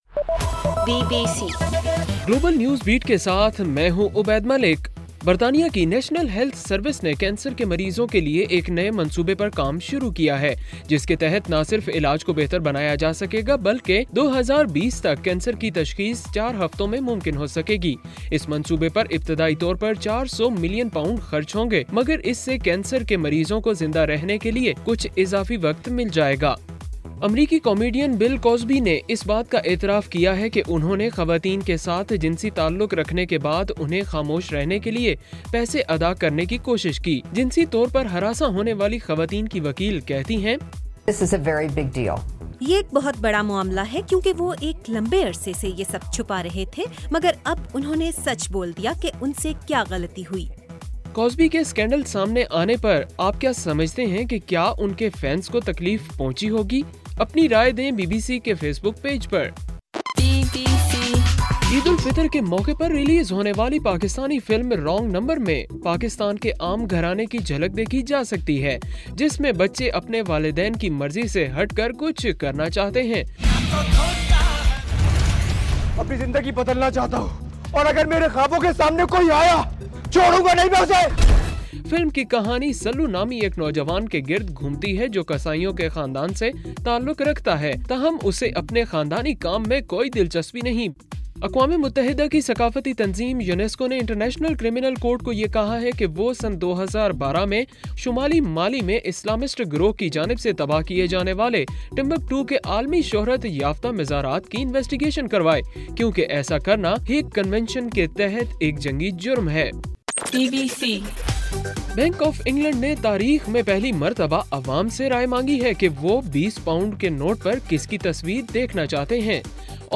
جولائی 19: رات 11 بجے کا گلوبل نیوز بیٹ بُلیٹن